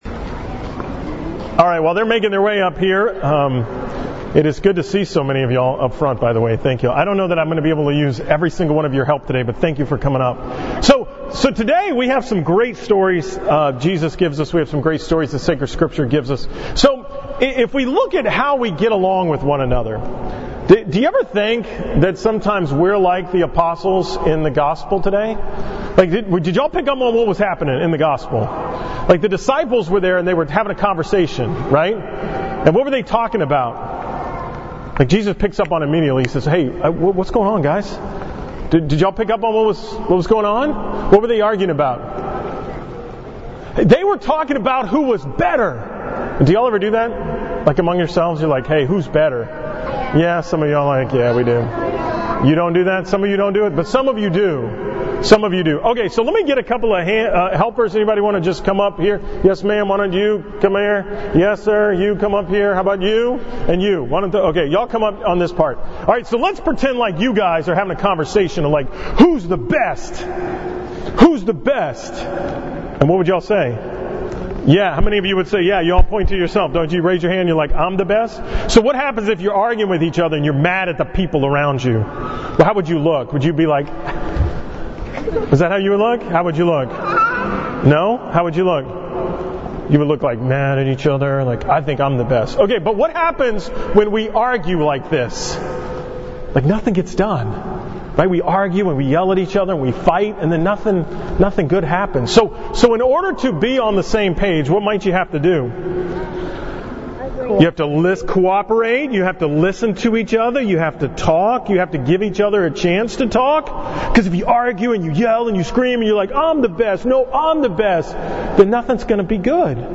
From the 9 am Mass at St. Martha's on September 23, 2018